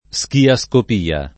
[ S kia S kop & a ]